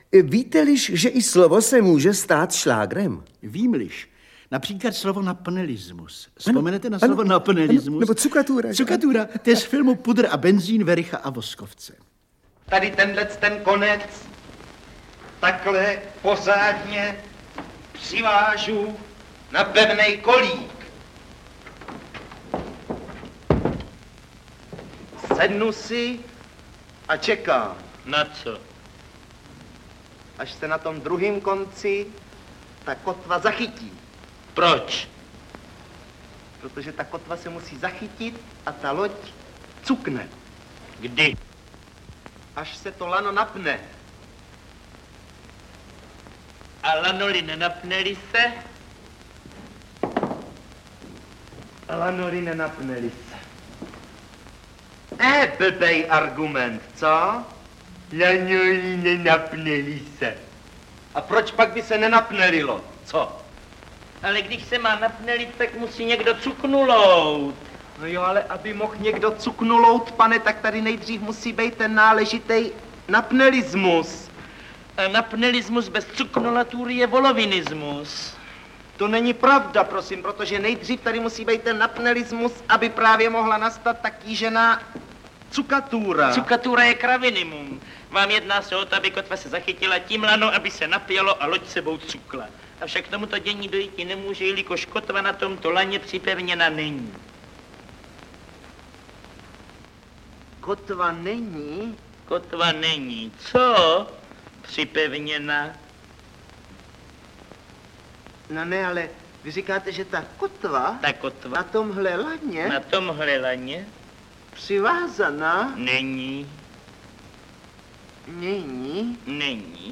Audiobook
Audiobooks » Humour, Satire & Comedy
Read: Jiří Voskovec